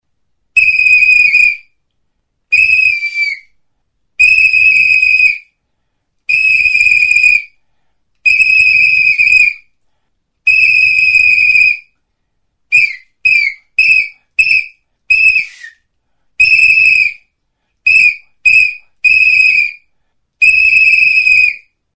Aerophones -> Flutes -> Fipple flutes (one-handed)
Recorded with this music instrument.
Txioka egiteko barruan plastikozko piezatxo bat du.